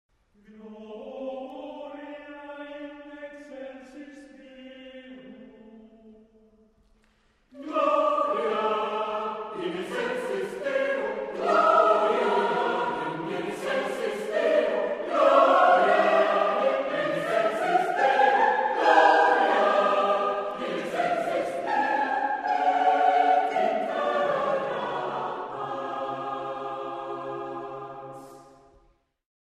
Voicing: satb Accompaniment: a cappella
sacred